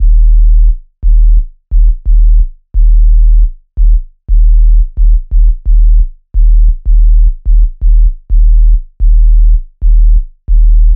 适合低音驱动的音乐，如dubstep或DnB。
标签： 175 bpm Dubstep Loops Bass Synth Loops 1.85 MB wav Key : C Ableton Live
声道立体声